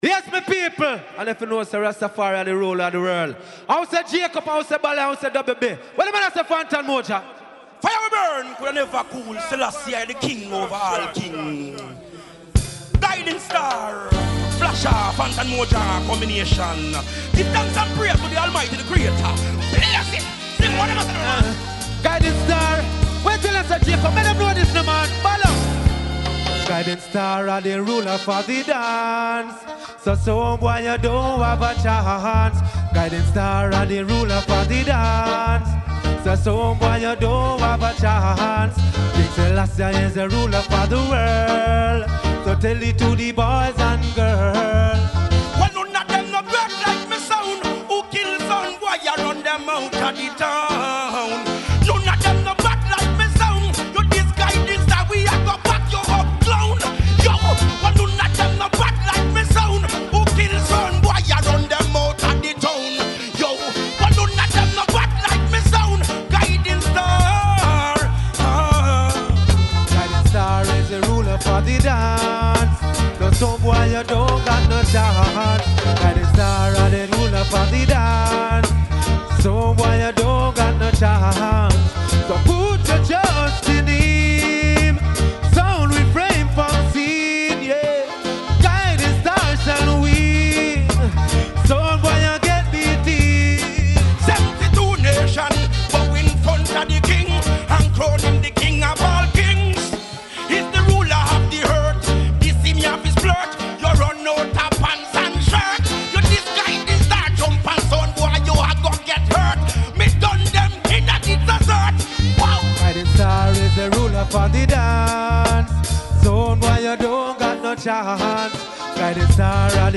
Raggae sound System music